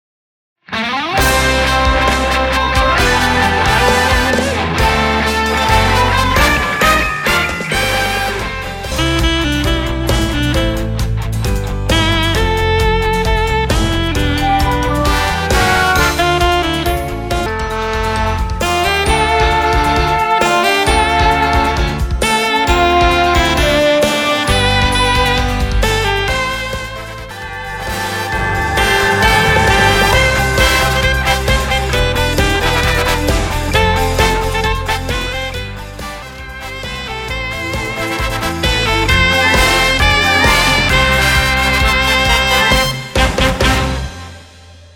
難易度 分類 並足１３３ 時間 ３分２６秒
編成内容 大太鼓、中太鼓、小太鼓、シンバル、トリオ 作成No ２５３